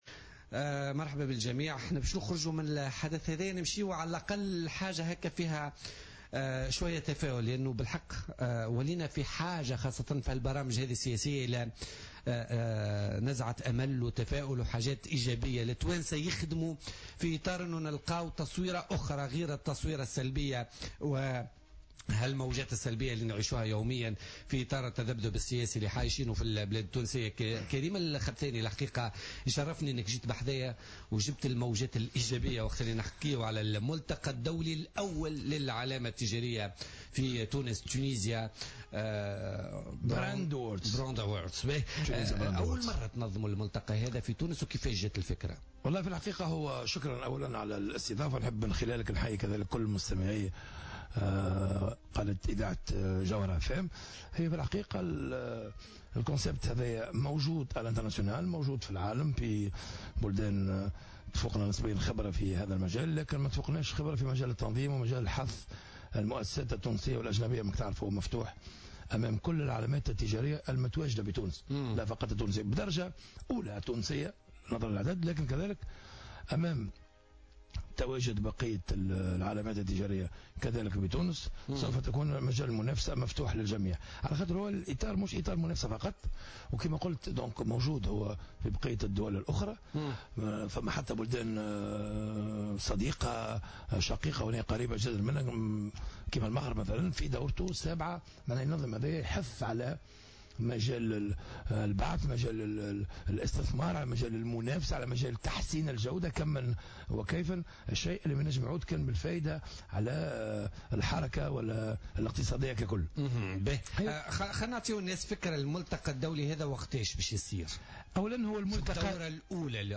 ضيف "بوليتيكا"